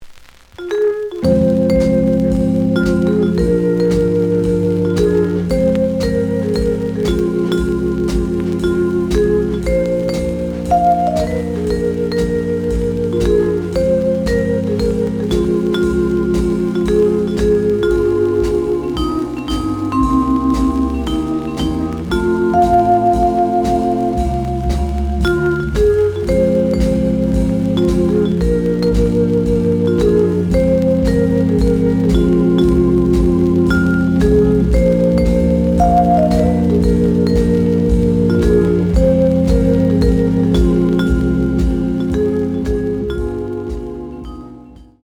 The audio sample is recorded from the actual item.
●Genre: Cool Jazz
Some noise on both sides.